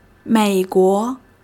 mei3--guo2.mp3